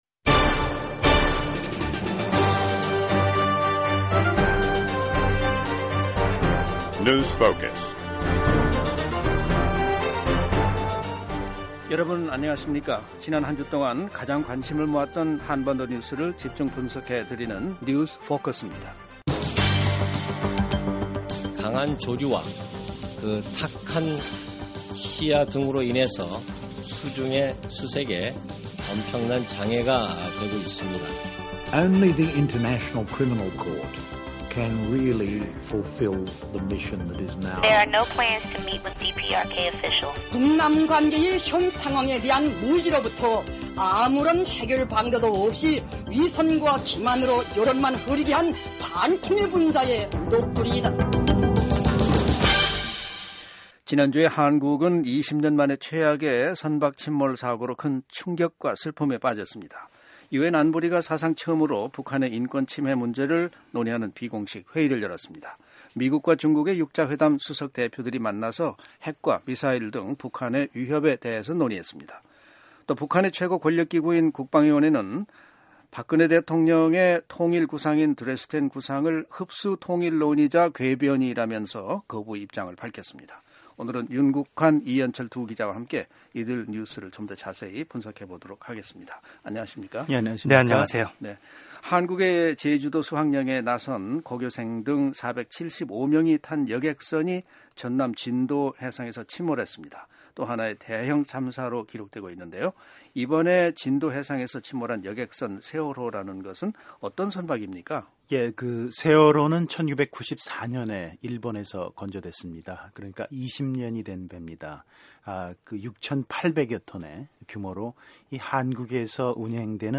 지난 한주 동안 가장 관심을 모았던 한반도 뉴스를 집중 분석해드리는 뉴스 포커스입니다. 오늘은 한국 여객선 진도 해상 침몰 소식, 유엔 안보리의 북한인권 관련 비공식 회의 소식 살펴봅니다. 또, 미국과 중국의 6자회담 수석대표 회동 소식과 북한이 한국 대통령의 통일 구상인 드레스덴 구상을 거부한 소식도 알아봤습니다.